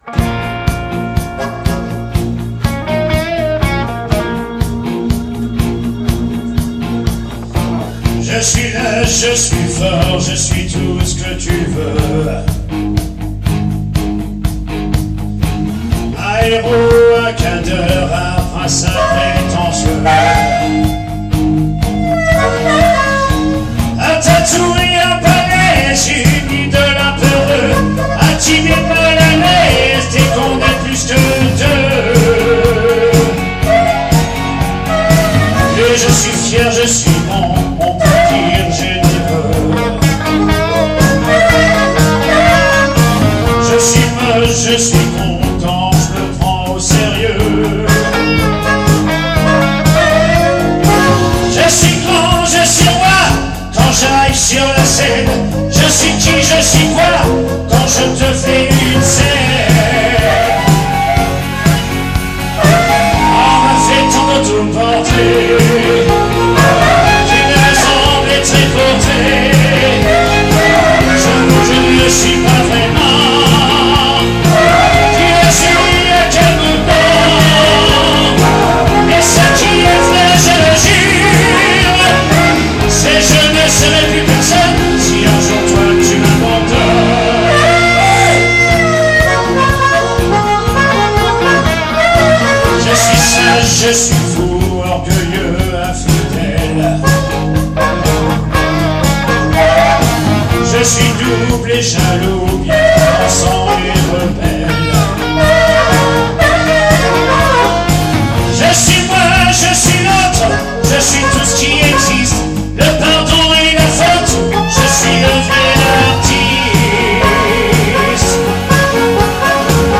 SOIREES BLUES-ROCK RETROSPECTIVE
DUO CHANT/HARMONICA
maquettes
soiree bistrot d'oliv - 14-12-19